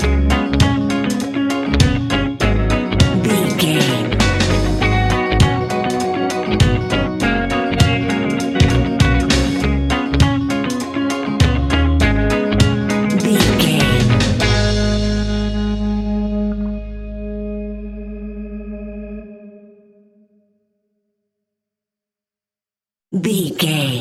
A nice bouncy and upbeat piece of Reggae music.
Aeolian/Minor
G#
Slow
laid back
chilled
off beat
drums
skank guitar
hammond organ
percussion
horns